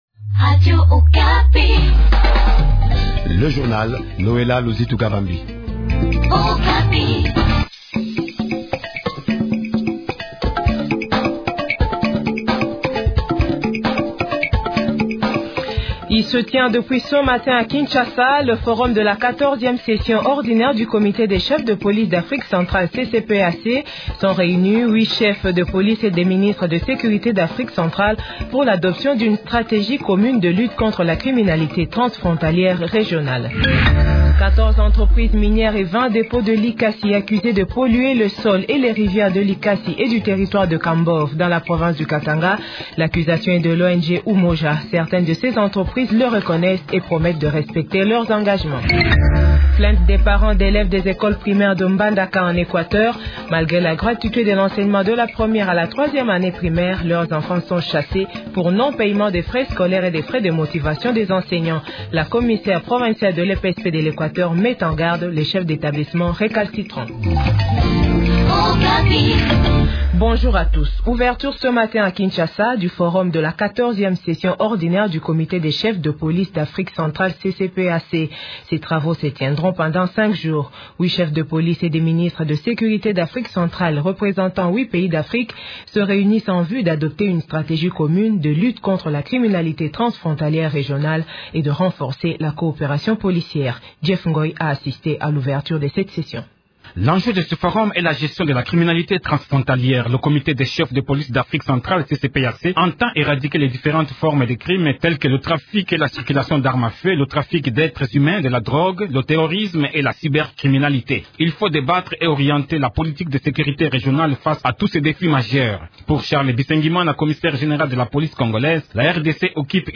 Journal Français Midi